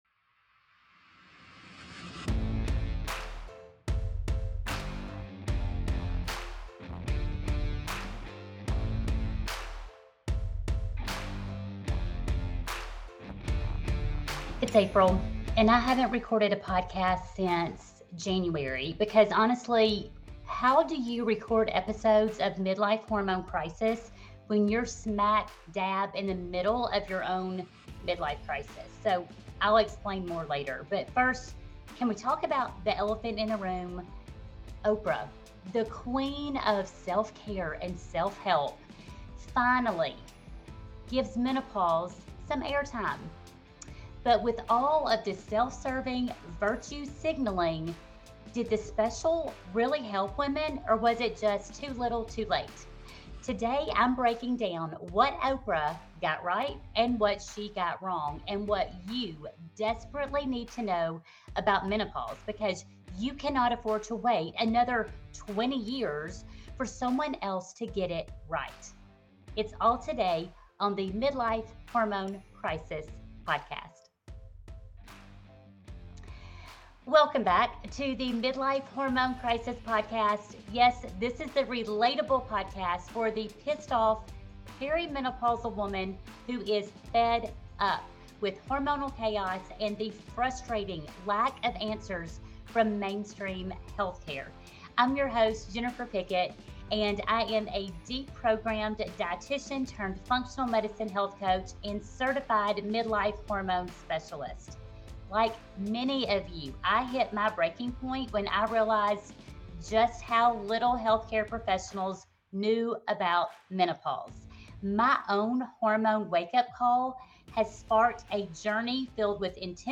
Yep, I totally admit that I may come across as a little unhinged in this episode!